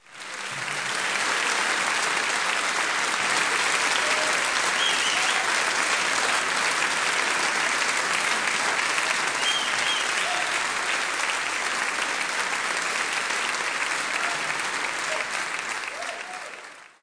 Sceneclear Audience Sound Effect
sceneclear-audience.mp3